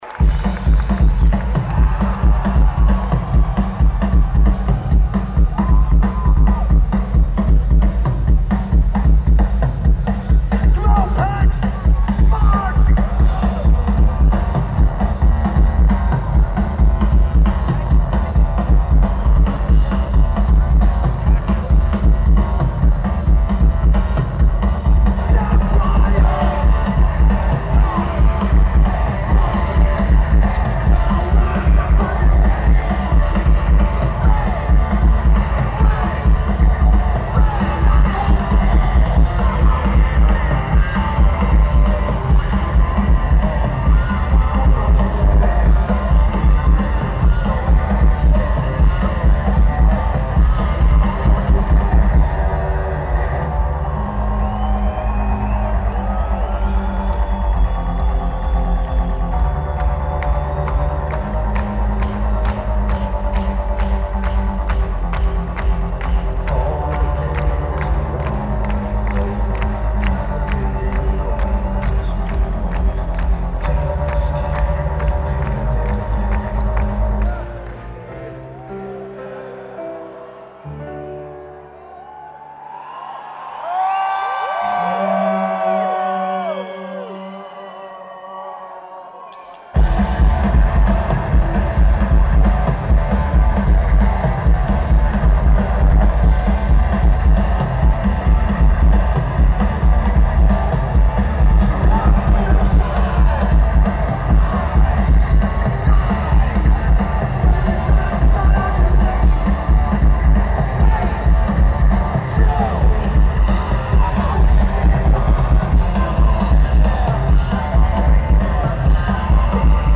Drums
Guitar
Lineage: Audio - AUD (Sony PCM-M10)
Recorded from in front of the soundboard.